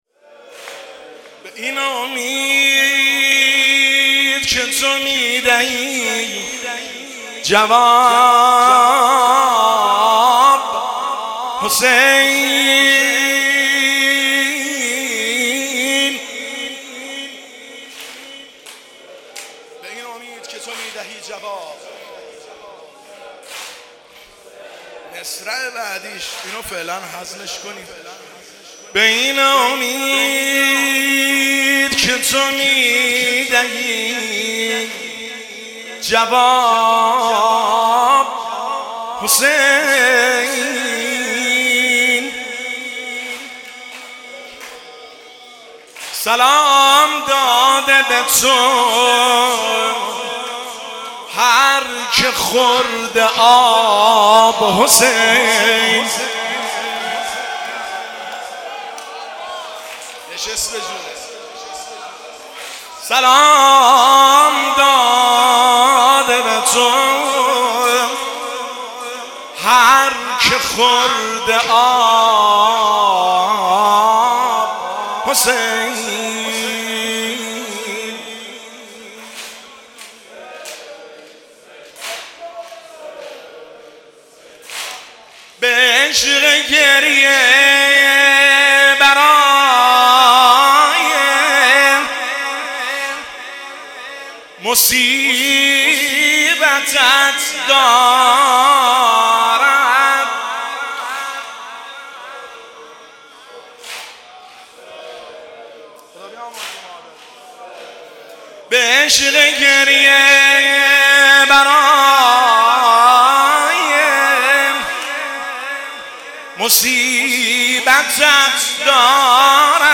شعرخوانی – شب شهادت امام جعفر صادق (ع) 1401